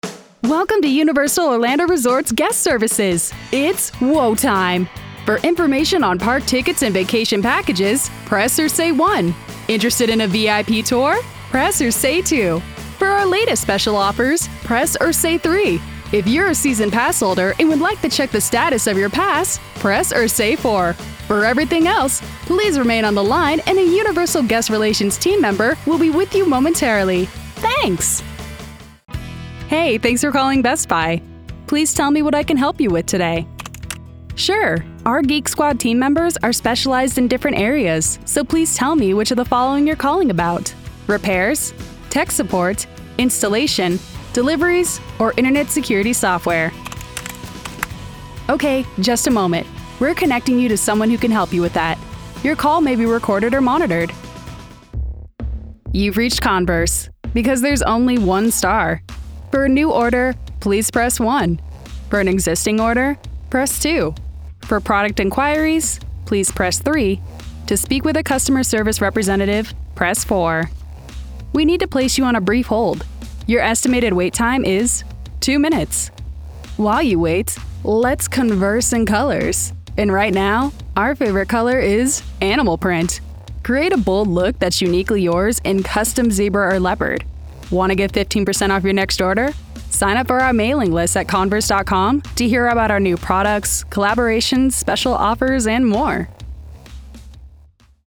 Voiceover Artist,
Sex: Female
Ages Performed: Teen, Young Adult, Middle Age,
Rode NT2-A, Focusrite Scarlett Solo 2nd gen 2-in/2-out, Source Connect standard, Computer
Demos